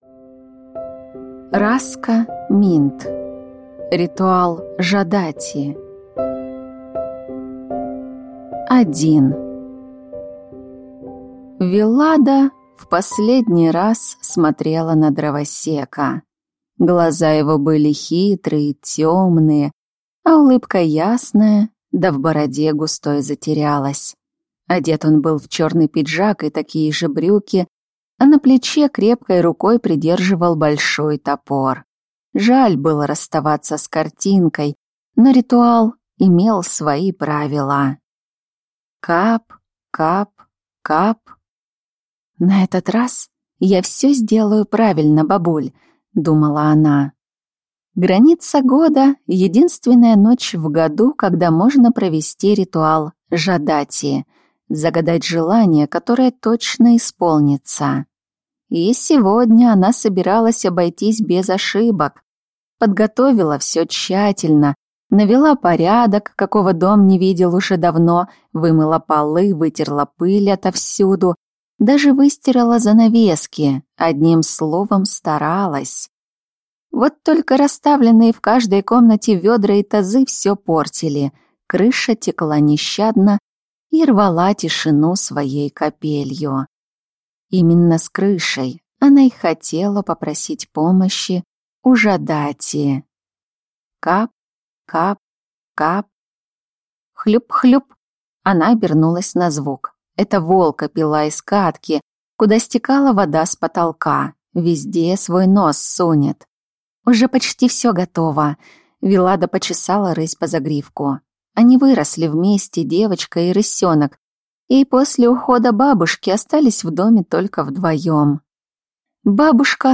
Аудиокнига Ритуал Жадати | Библиотека аудиокниг